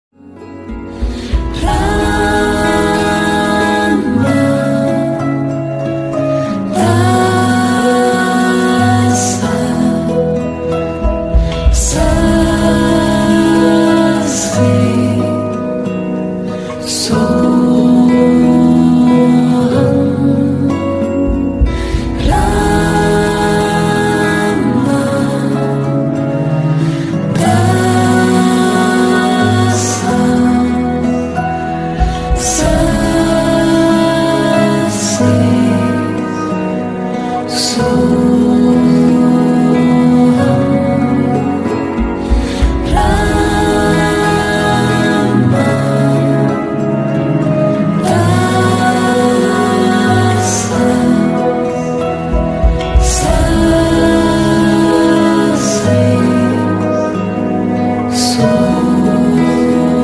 سپس باید به اندازه چهار ضربه بین اولین “سا” و دومین “سا” فاصله باشد.
“هانگ” باید طوری ادا شود که ارتعاشش در بینی احساس شود.